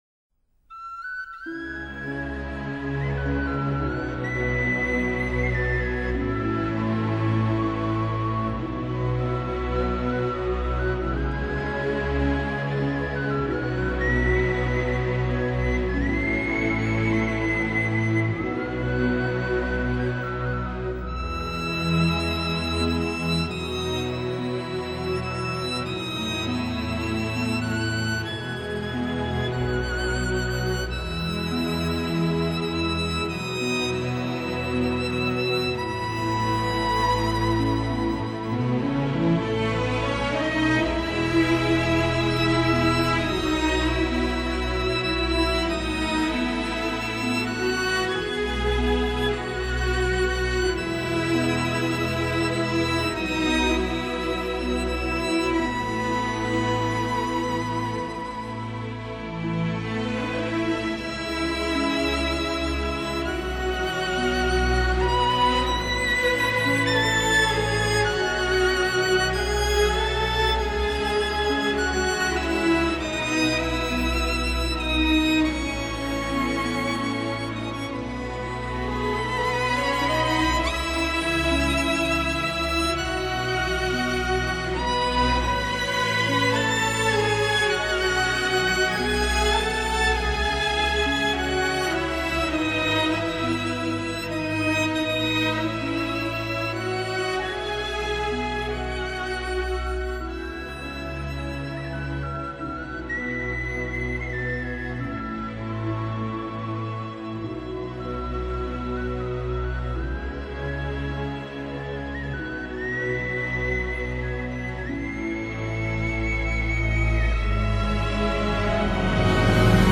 融合古典与轻音乐大乐团的演出方式